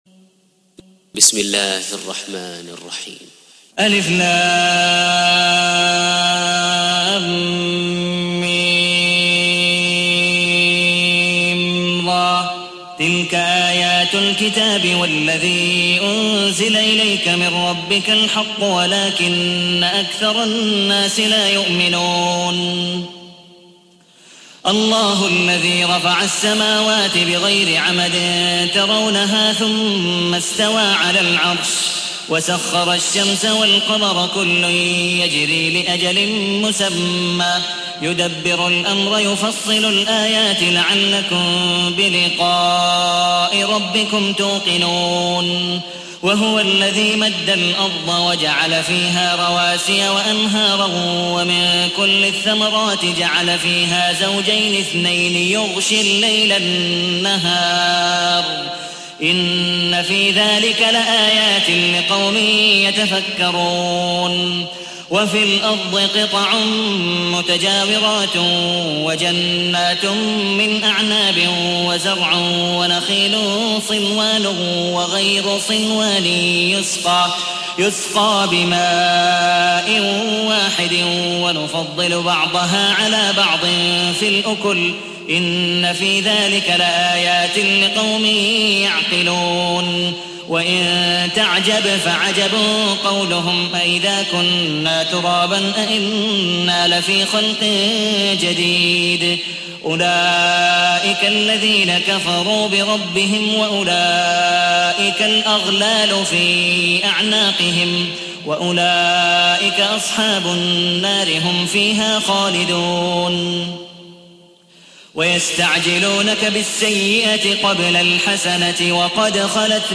تحميل : 13. سورة الرعد / القارئ عبد الودود مقبول حنيف / القرآن الكريم / موقع يا حسين